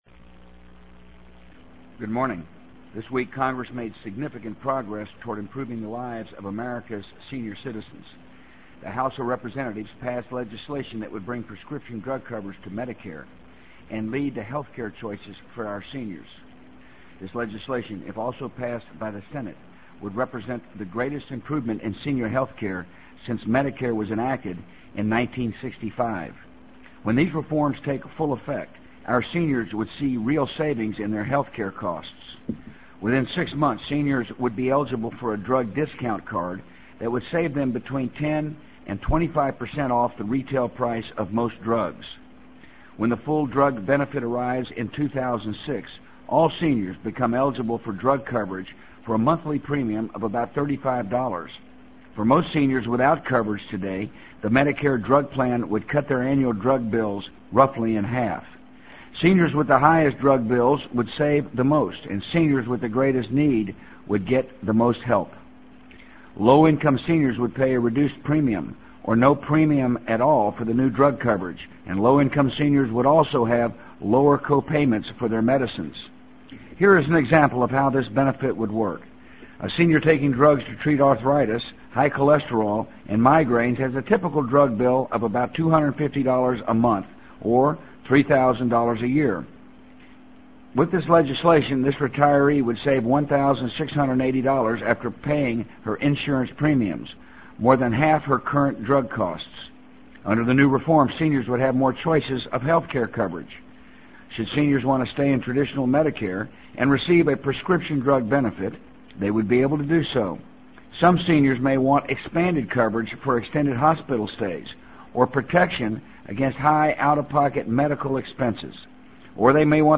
【美国总统George W. Bush电台演讲】2003-11-22 听力文件下载—在线英语听力室